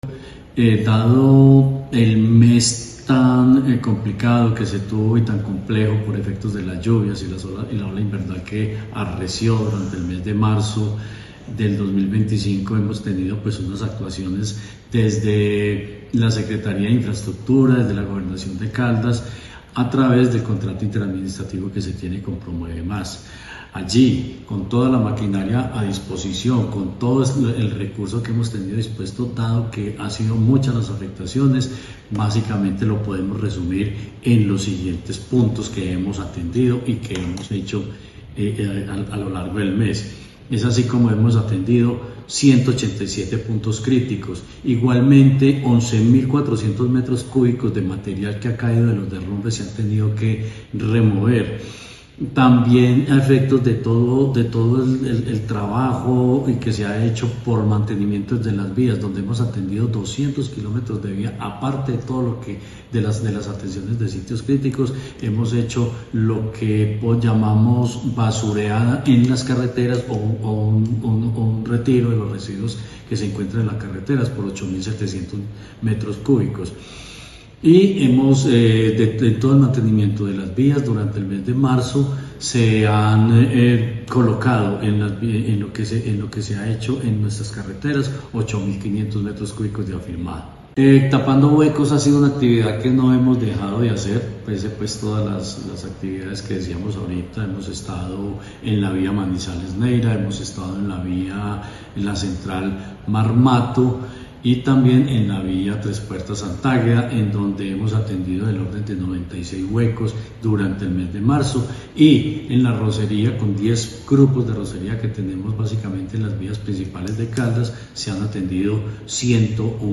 Secretario de Infraestructura, Jorge Ricardo Gutiérrez Cardona.